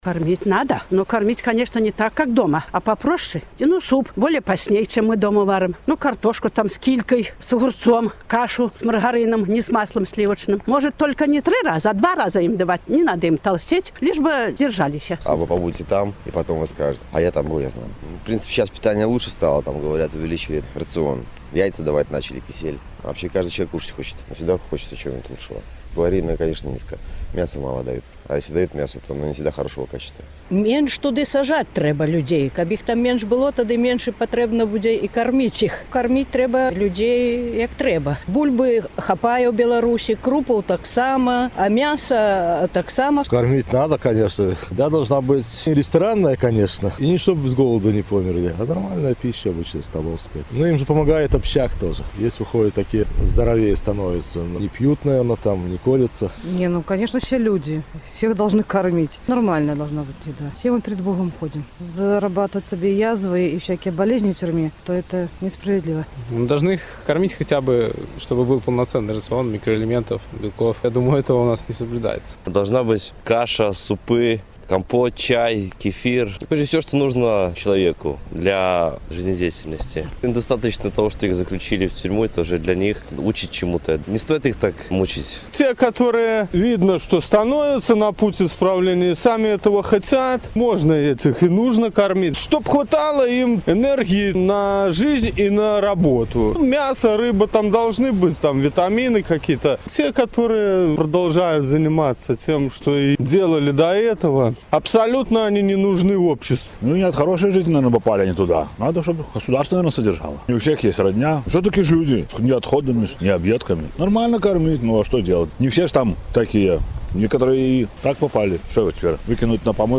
Ці варта карміць зэкаў? Апытаньне ў Магілёве